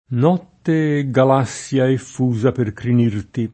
Galassia [gal#SSLa] n. pr. f. astron. — es. con acc. scr.: Notte, e Galàssia effusa per crinirti [